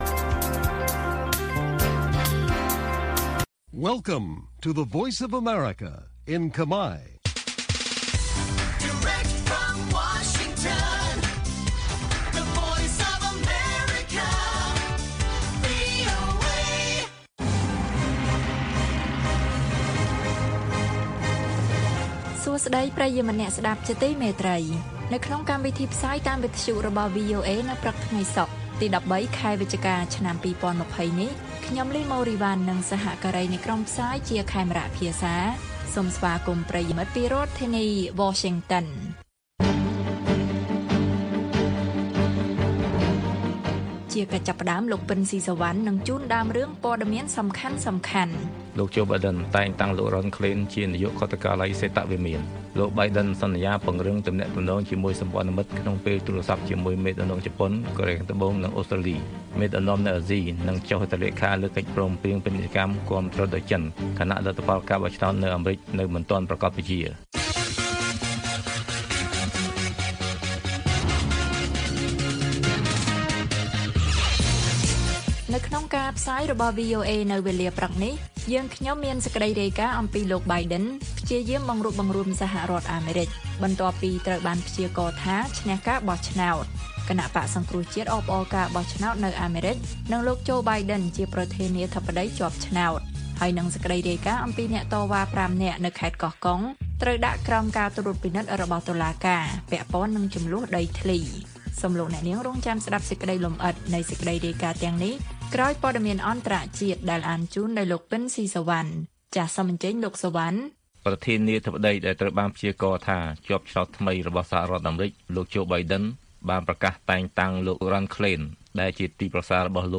ព័ត៌មានពេលព្រឹក៖ ១៣ វិច្ឆិកា ២០២០